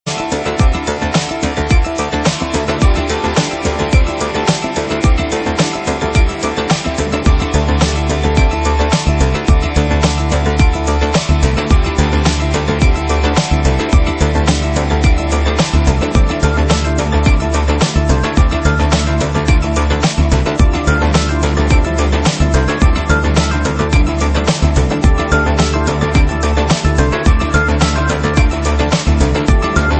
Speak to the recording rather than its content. [Lo-Fi preview]